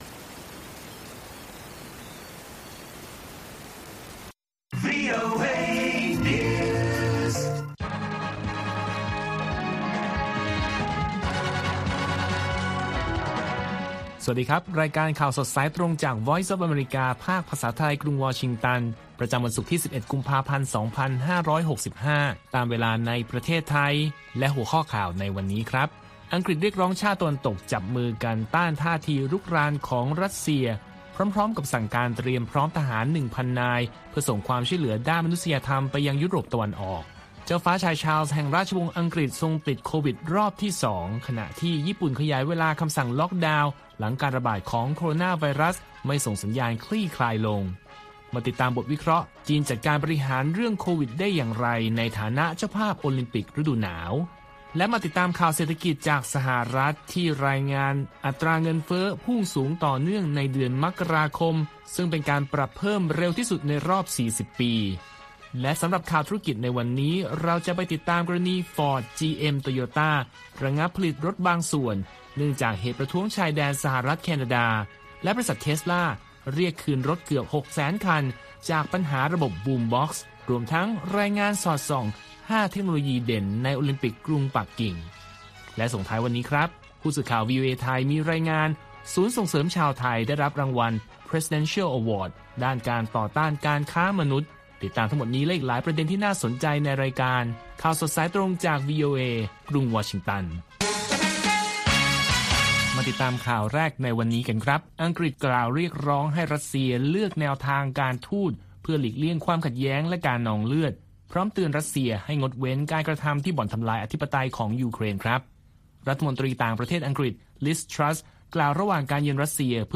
ข่าวสดสายตรงจากวีโอเอ ภาคภาษาไทย ประจำวันศุกร์ที่ 11 กุมภาพันธ์ 2565 ตามเวลาประเทศไทย